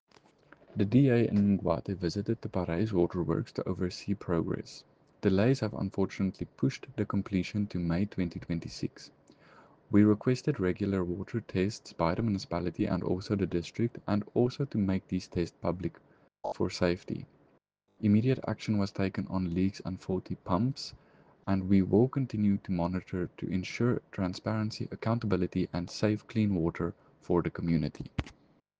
Afrikaans soundbites by Cllr JP de Villiers and